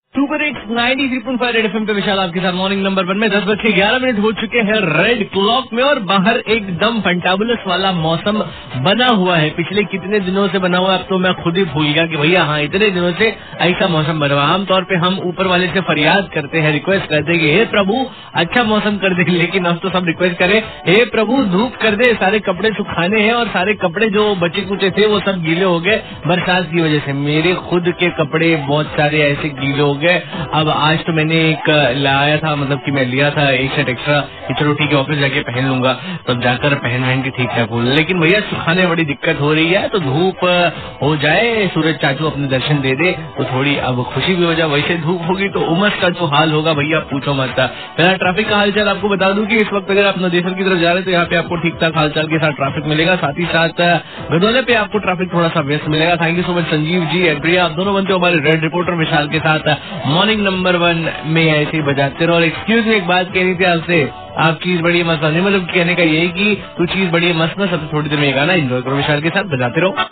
RJ TALKING ABOUT WEATHER